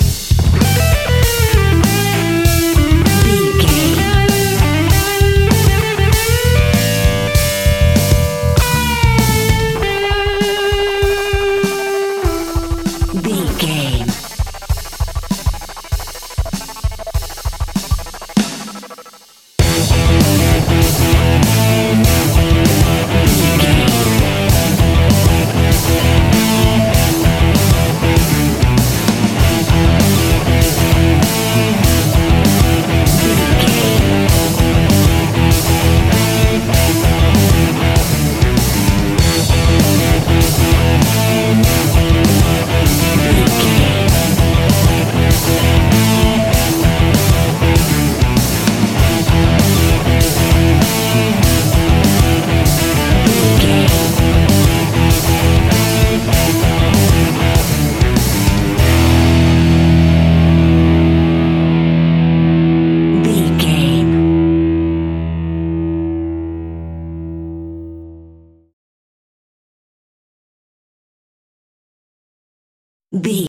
Ionian/Major
energetic
driving
heavy
aggressive
electric guitar
bass guitar
drums
hard rock
heavy metal
distortion
distorted guitars
hammond organ